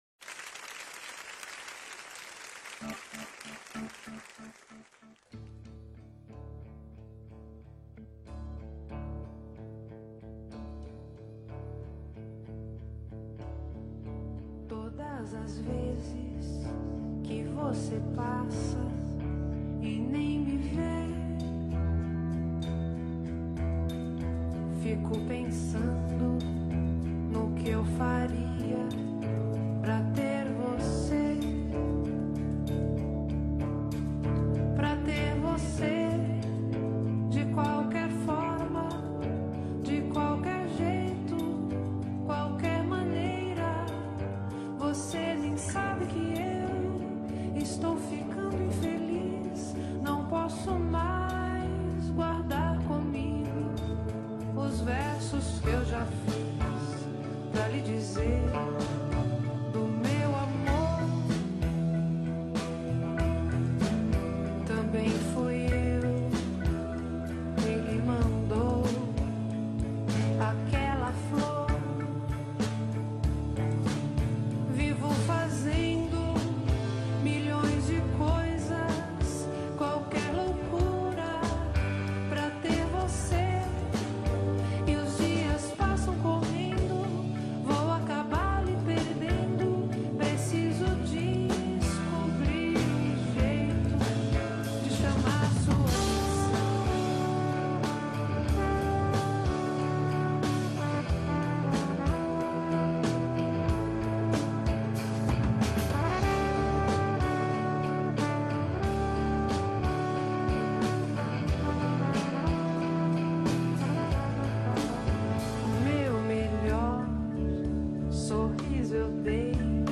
bateria
baixo
guitarra
trumpete
teclados